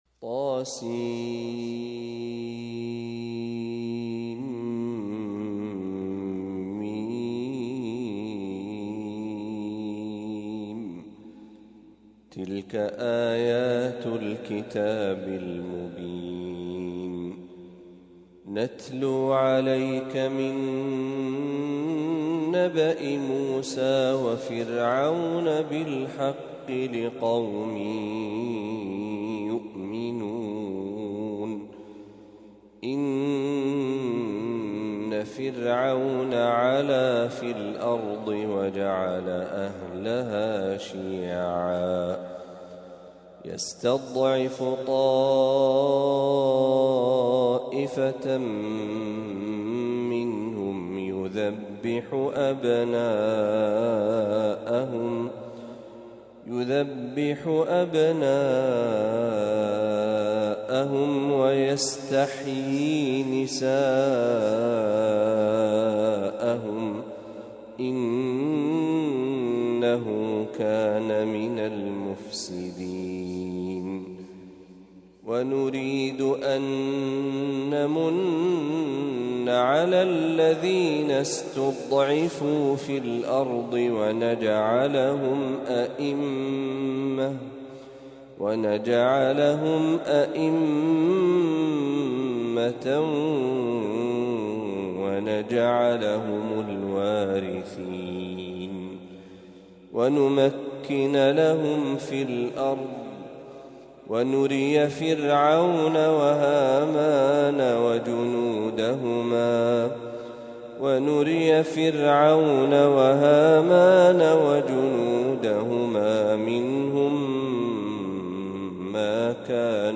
تلاوة لسورة القصص كاملة | من فجريات شهر ذي الحجة ١٤٤٥هـ > 1446هـ > تلاوات الشيخ محمد برهجي > المزيد - تلاوات الحرمين